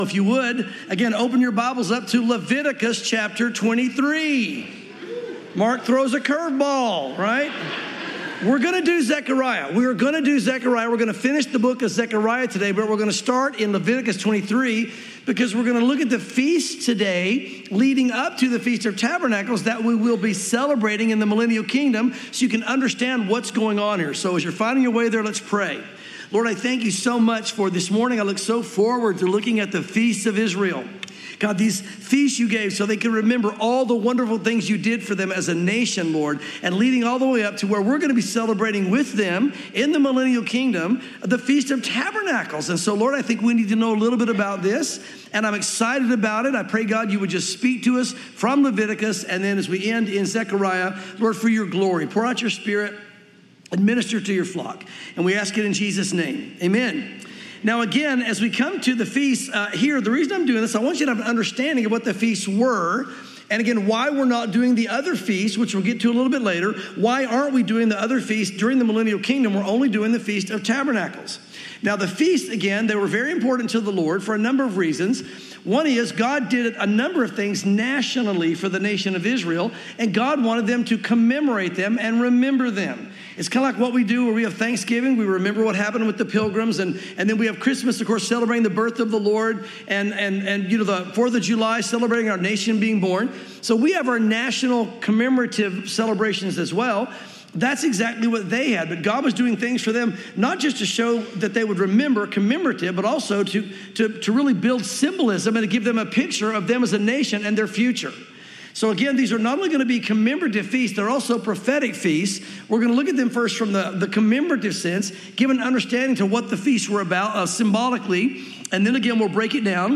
sermons Zechariah 14:16-21 | The Feast of Tabernacles in the Millennial Kingdom